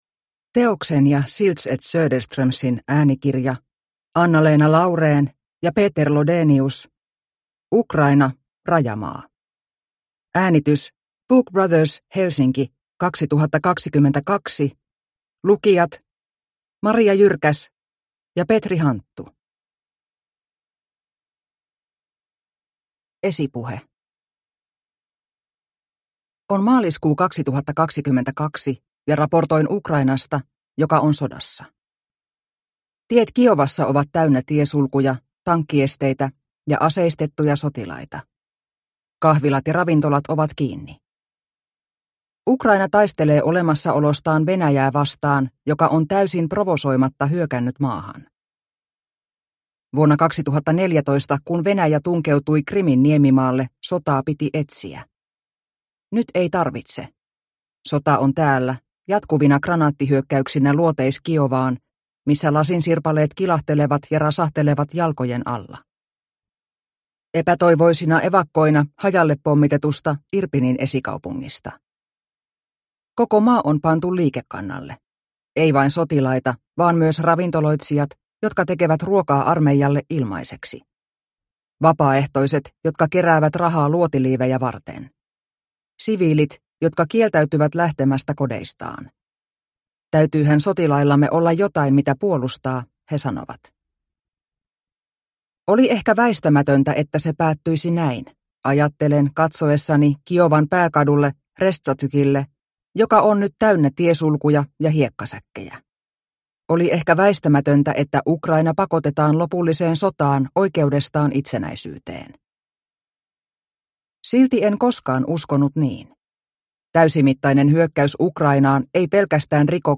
Ukraina - rajamaa – Ljudbok – Laddas ner